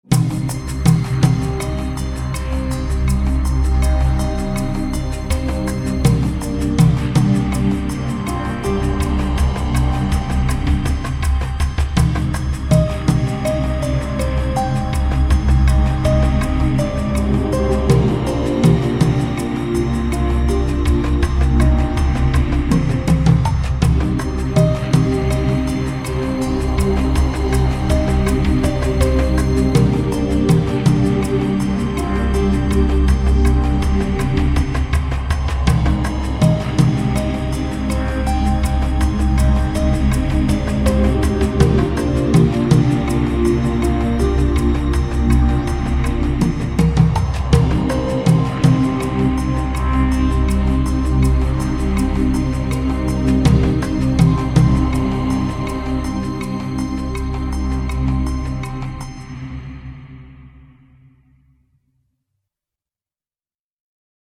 Soundtrack with an African Feel!
Tribal drums, moving panoramic
themes and atmospheres with beautiful African voices